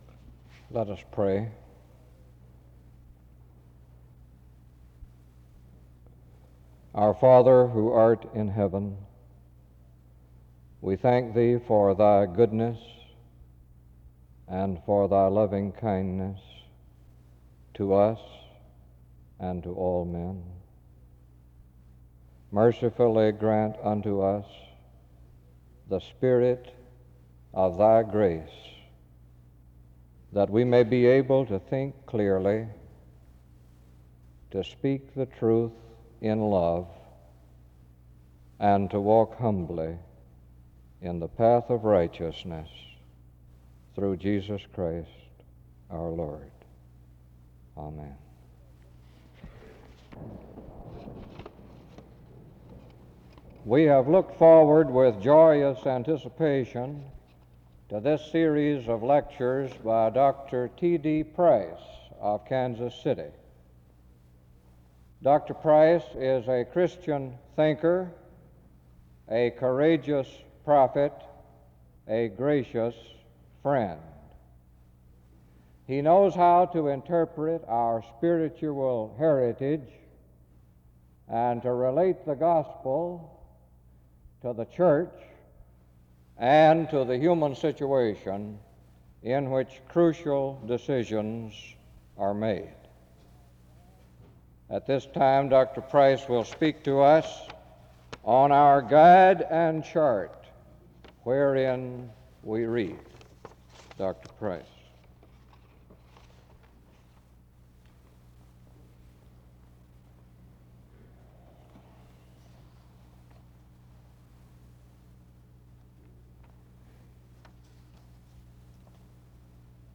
He ends his time in prayer (53:33-54:58), and organ music ends the service (54:59-56:49).